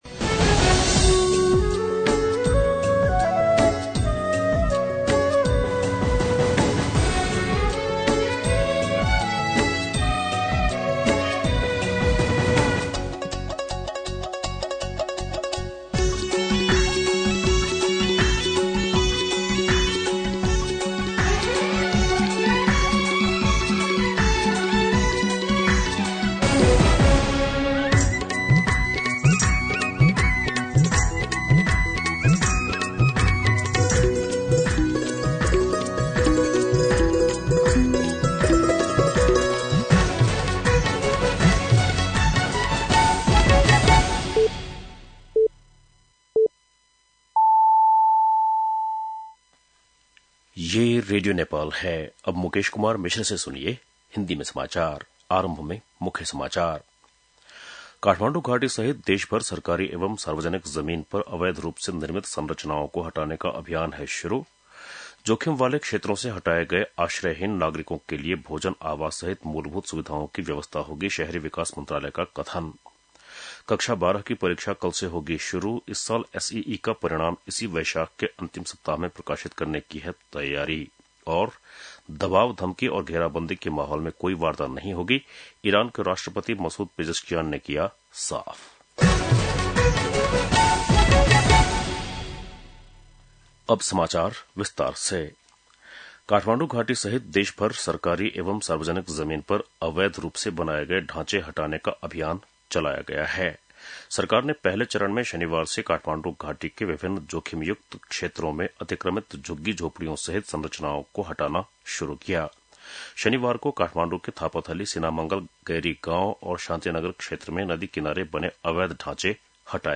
बेलुकी १० बजेको हिन्दी समाचार : १३ वैशाख , २०८३
10-PM-Hindi-NEWS-1-13.mp3